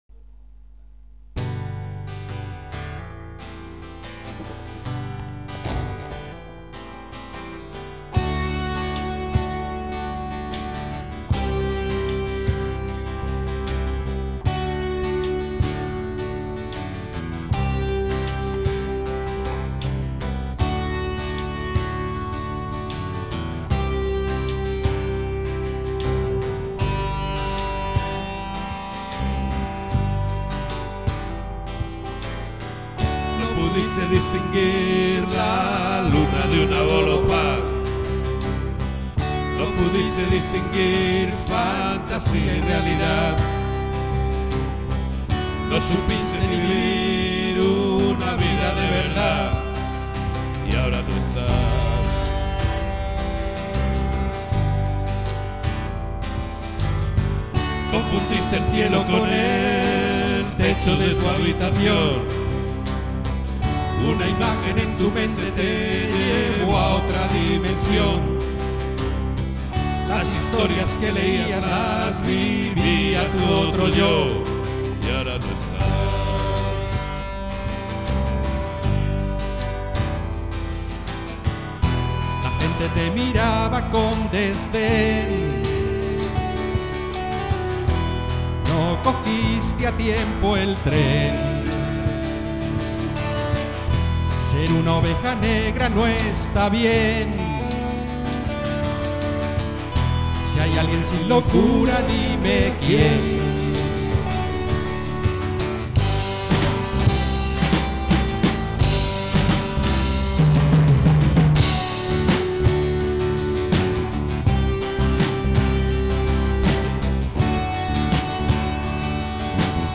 Batería y voz
Guitarra acústica y voz
Guitarra eléctrica
Bajo